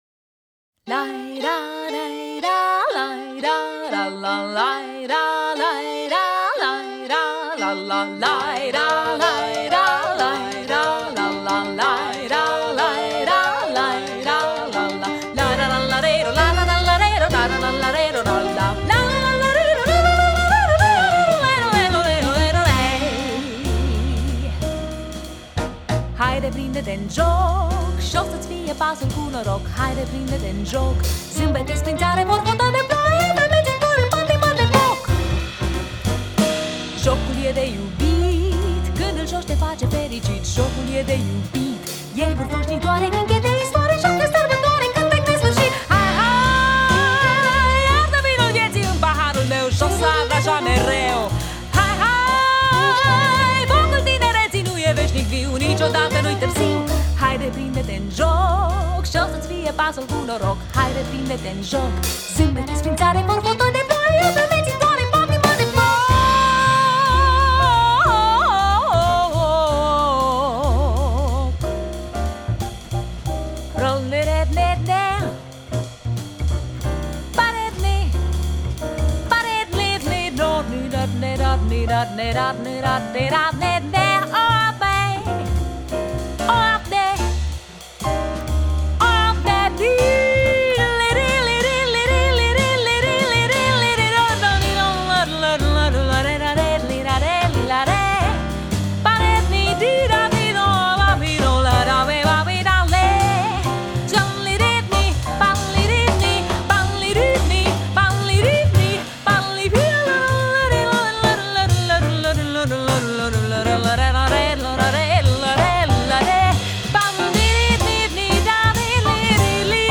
Äänitetty Kallio-Kuninkalassa, Järvenpäässä.